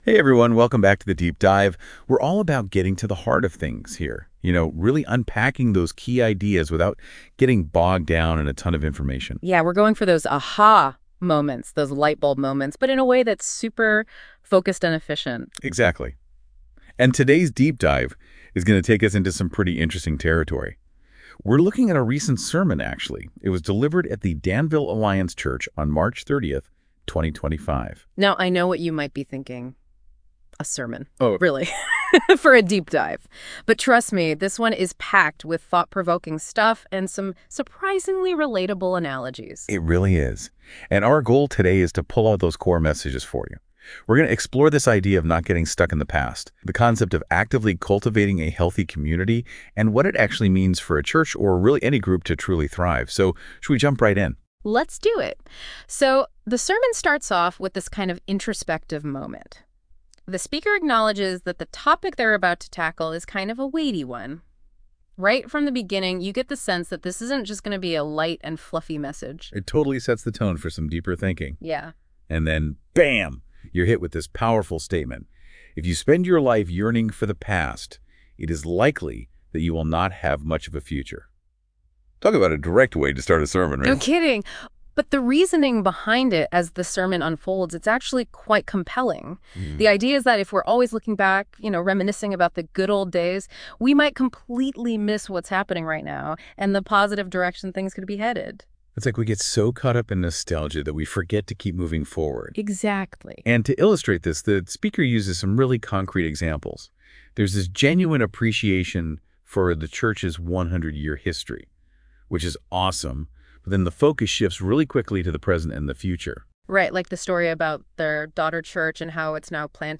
[NOTE: This "conversation" was created with the assistance of NotebookLM AI, and has been reviewed to ensure that an accurate presentation of the sermon is given .]